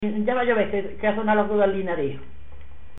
Secciones - Biblioteca de Voces - Cultura oral